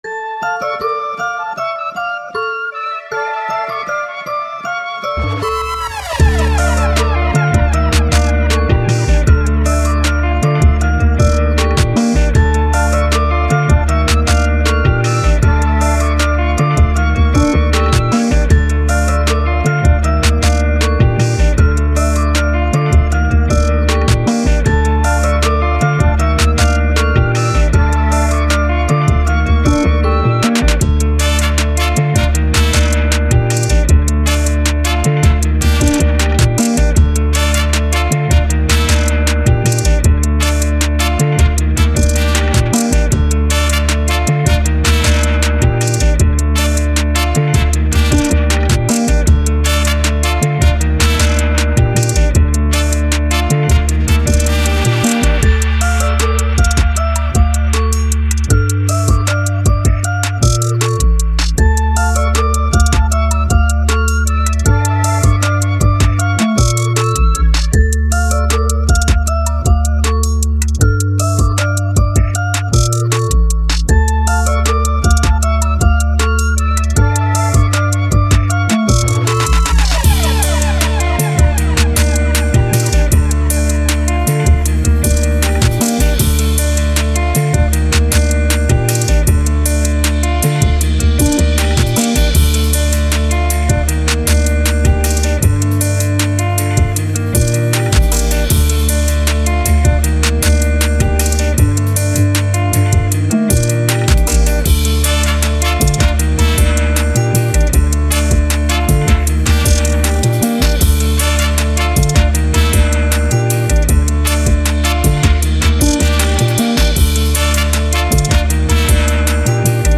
Hip Hop, Trap, Grime, Dubstep, Action